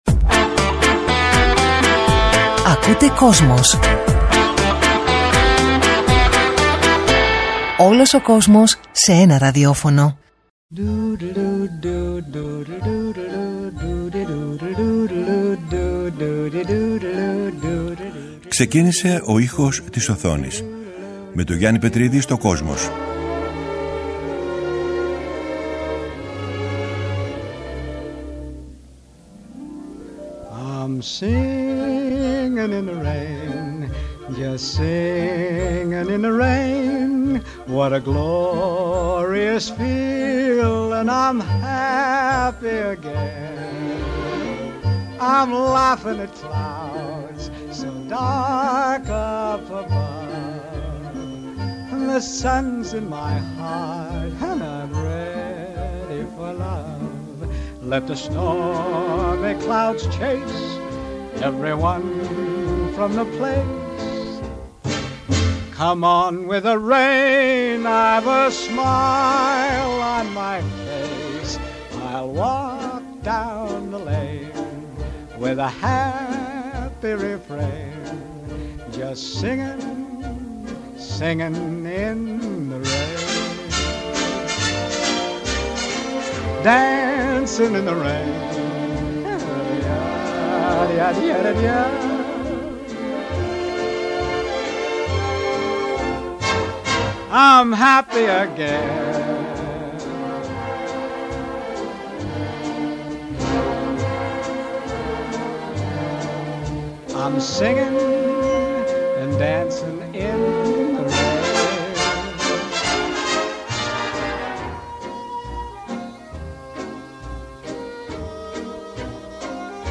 Ακούγονται αξέχαστες μελωδίες
Επίσης ακούγονται τραγούδια που γεννήθηκαν στη μεγάλη οθόνη
Κινηματογραφικη Μουσικη